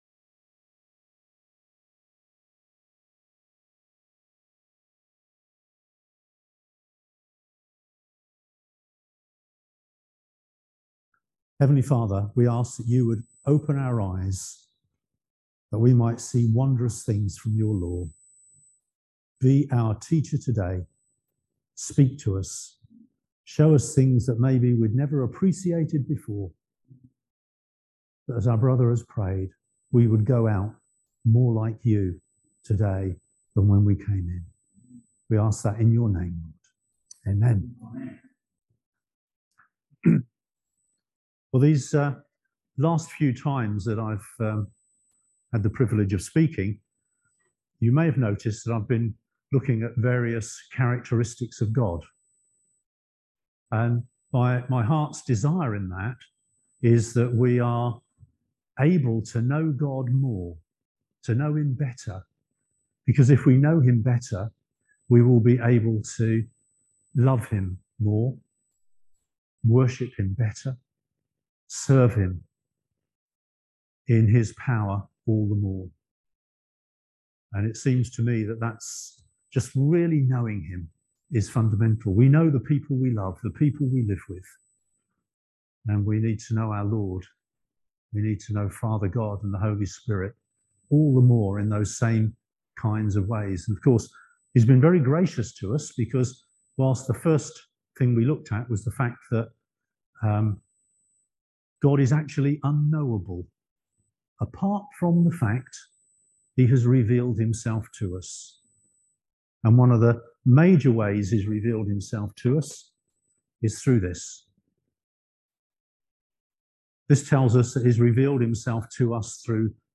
Service Type: Sunday Service Topics: Character of God , Faith , Faithfulness , Immutability , Prayer , Promises , Salvation